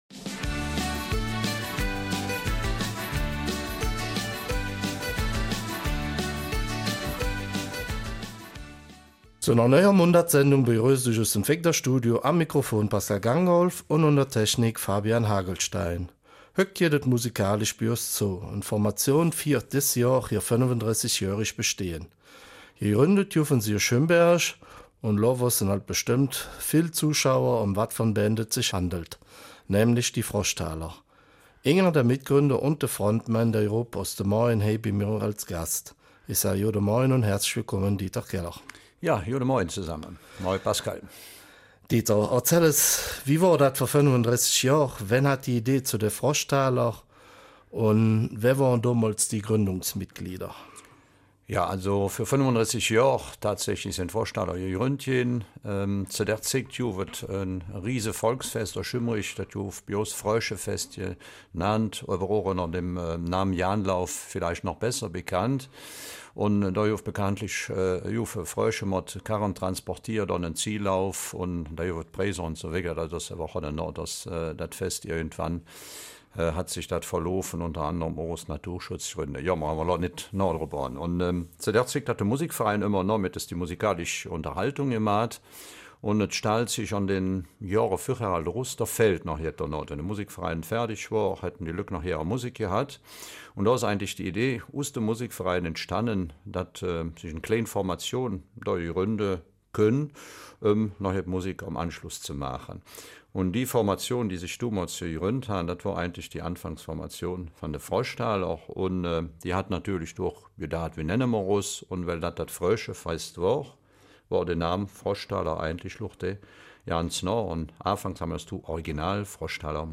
Eifeler Mundart: 35 Jahre Froschtaler